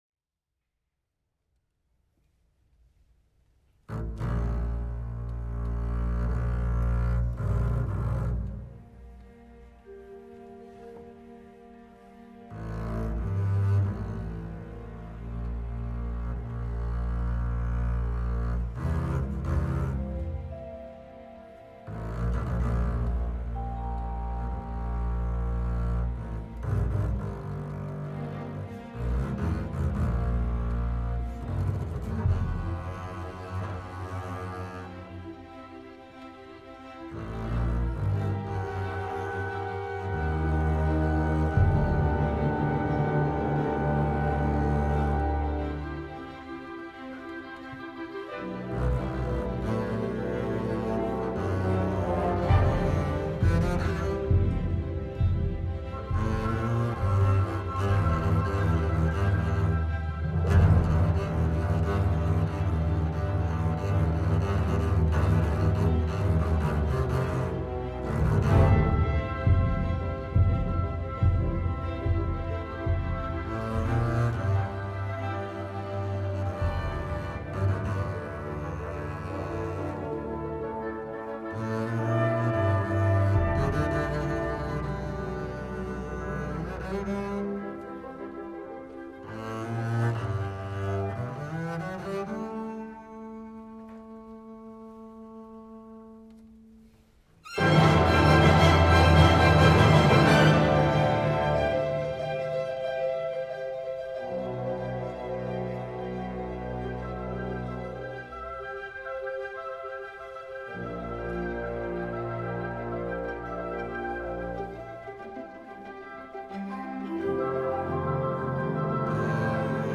ti 1 perc / db solo / strings